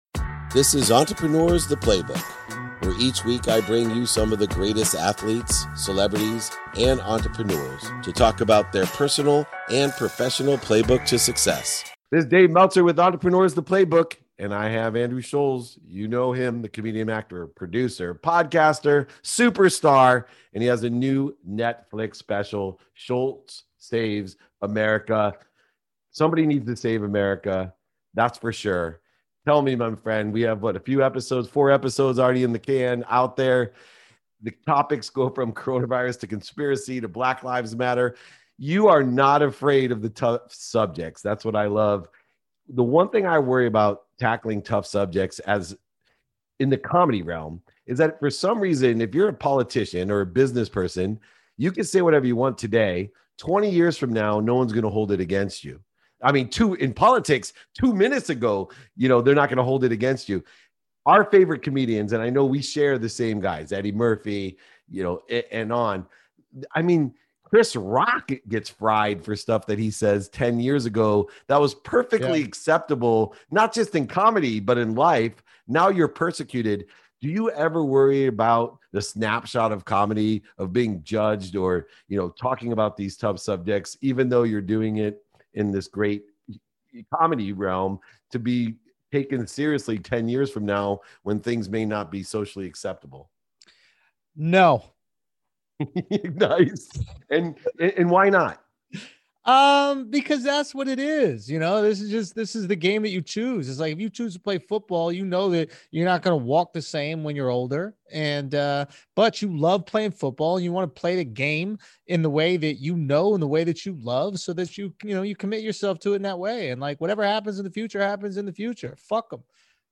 Today’s episode is from a 2021 conversation with the hilarious Andrew Schulz—a standup comedian, actor, and podcaster. We discuss the power of humor in entrepreneurship, dissect how authenticity shapes success and the role cancel culture plays in today's media environment. Andrew shares his unfiltered perspective on leveraging social media to break social norms and build a personal brand that resonates with a global audience. Tune in for a conversation filled with laughs and learnings on disrupting the conventional paths to success.